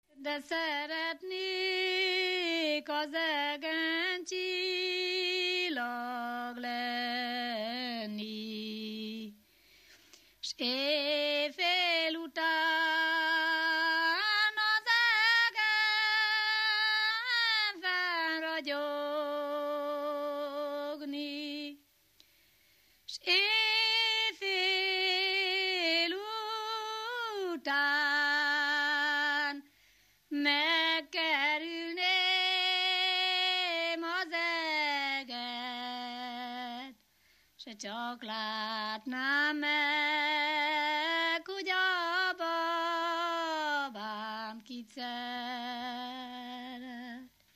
Erdély - Szolnok-Doboka vm. - Szék
ének
Műfaj: Lassú
Stílus: 3. Pszalmodizáló stílusú dallamok